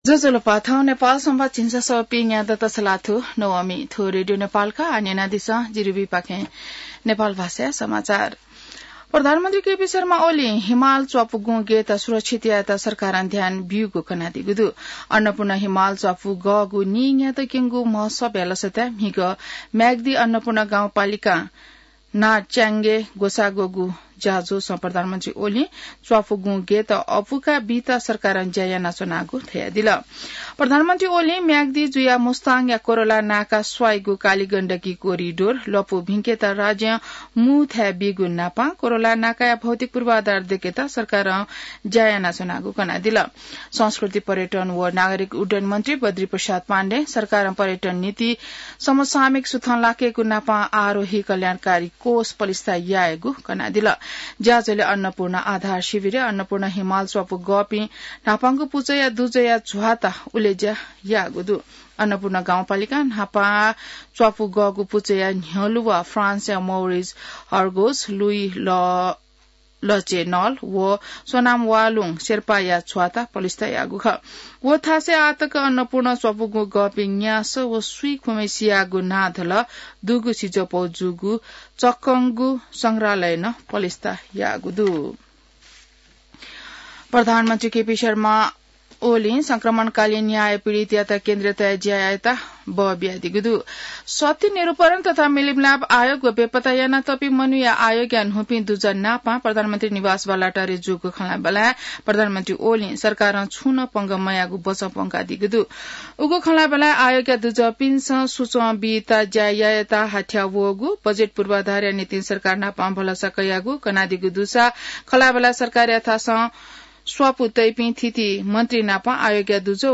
नेपाल भाषामा समाचार : २१ जेठ , २०८२